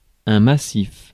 Ääntäminen
US : IPA : [bʌlk]